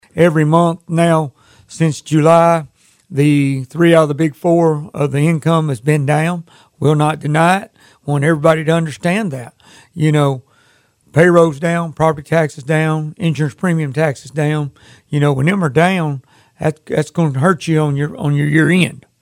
Mayor JR Knight says they have been tracking the city's revenue closely.